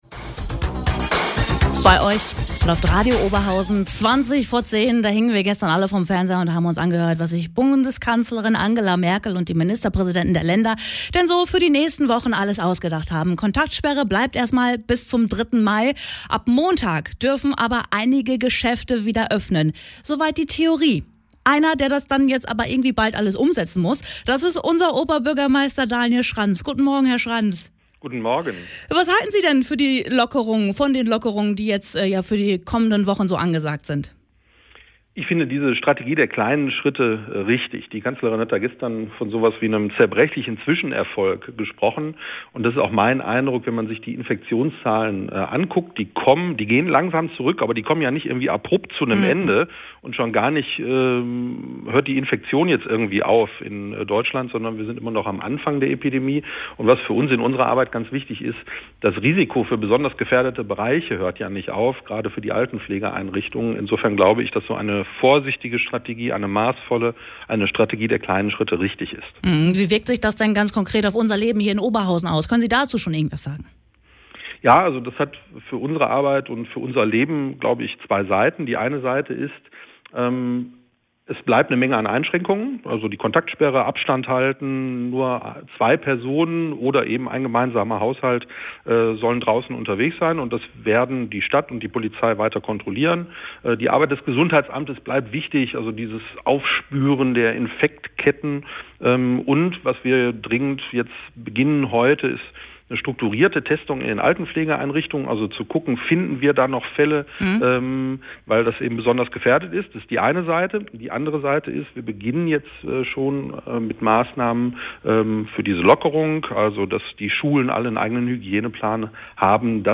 radio_oberhausen_interview_schranz.mp3